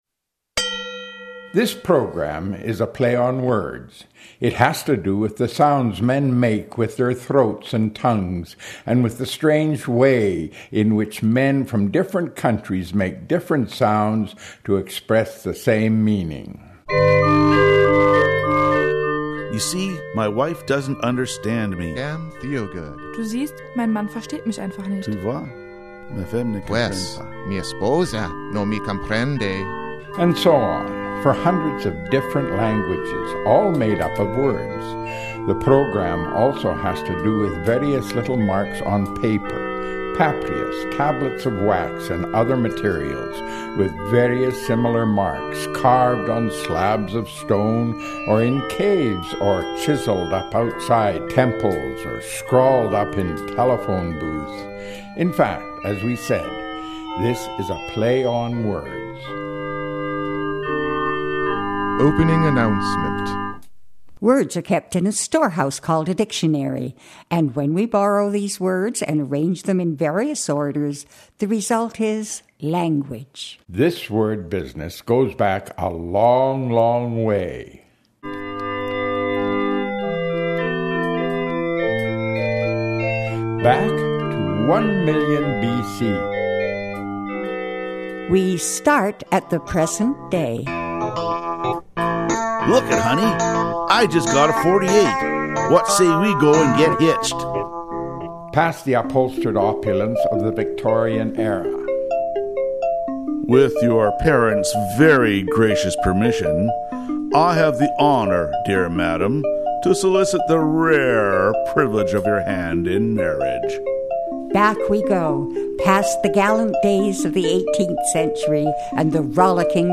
Radio Play on Voice of the Shuswap
It was aired on CKVS Voice of the Shuswap on November 11th.